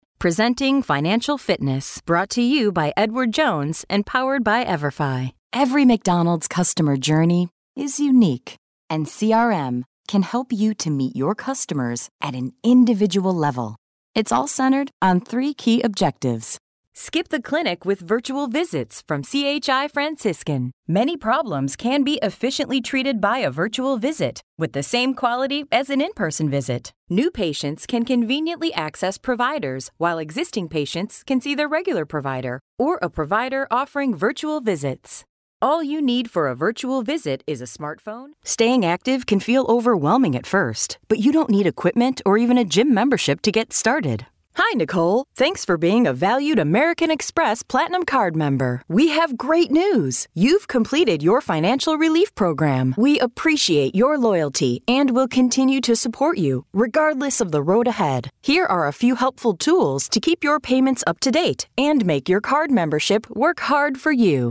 Narración
Micrófono MKH 415, Pro Tools, estudio insonorizado con funciones de masterización.